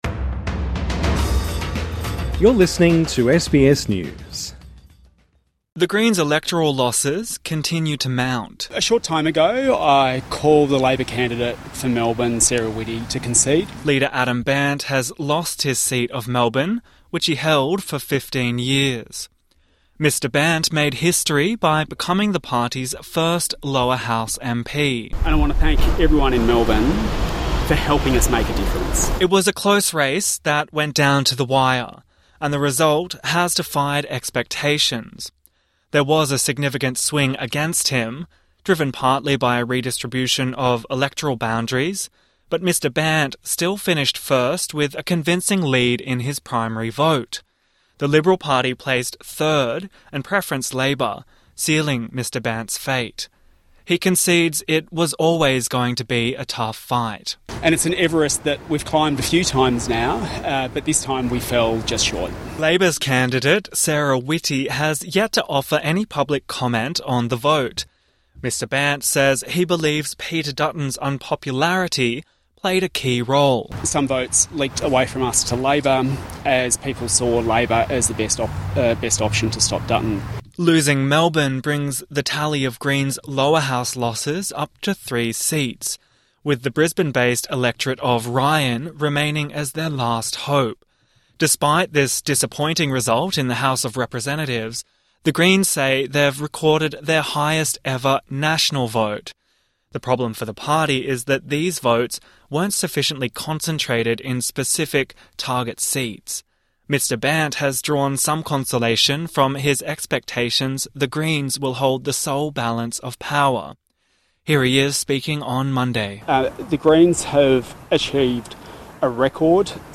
Adam Bandt concedes defeat at a media conference in Melbourne Source